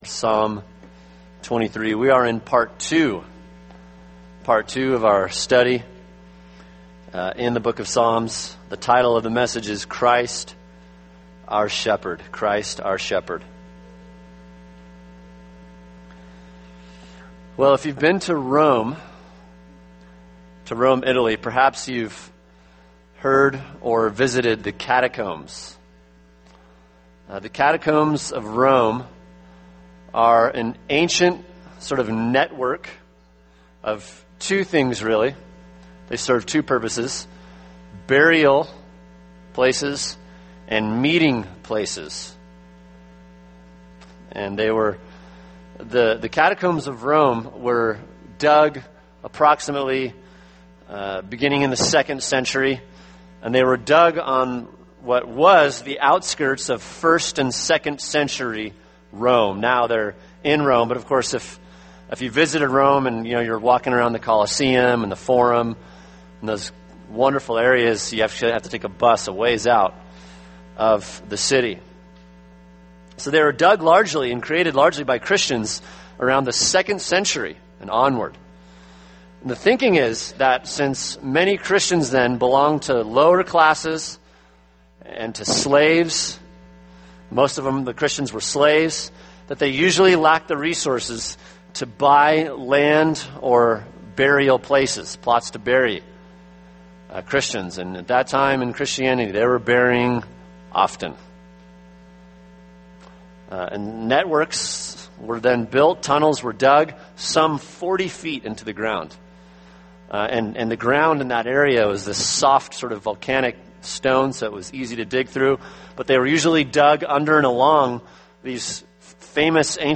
[sermon] Psalm 23 – Christ Our Shepherd (part 2) | Cornerstone Church - Jackson Hole